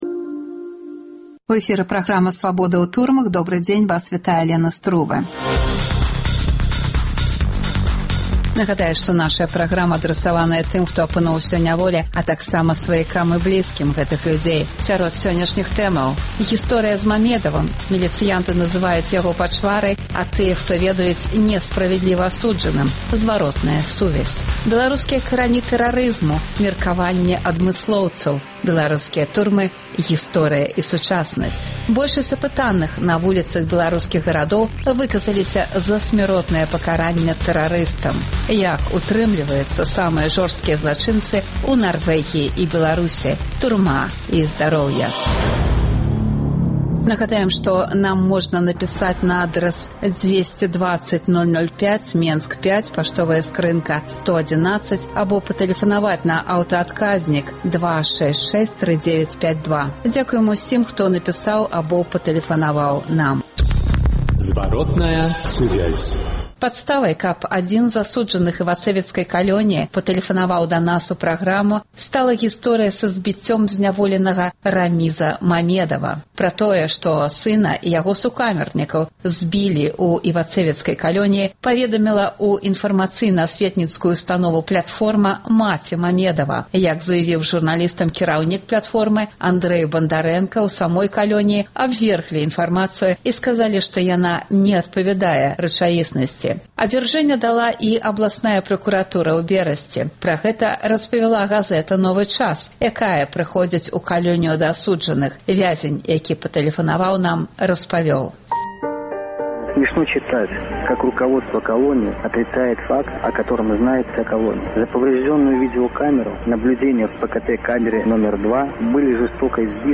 Апытаньні на вуліцах беларускіх гарадоў.